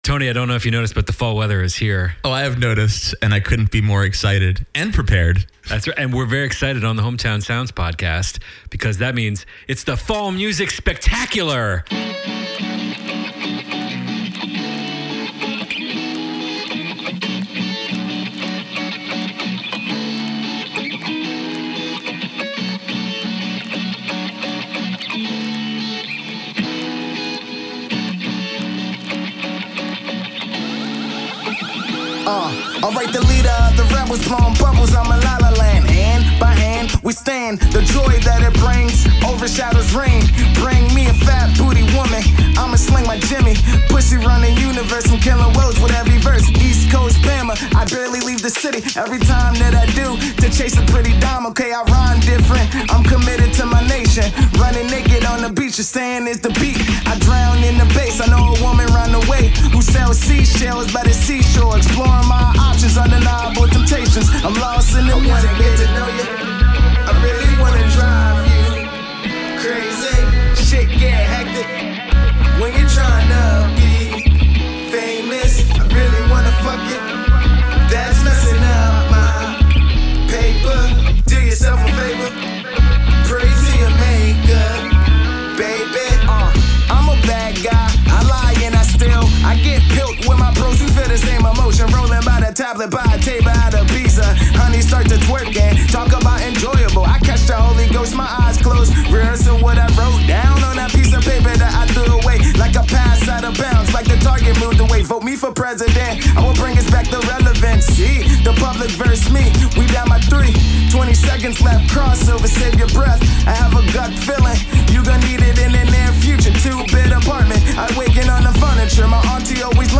And of course the hot new DC jams for the season!